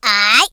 07d83af9d2a8 Add hillbilly voice
Yessir.ogg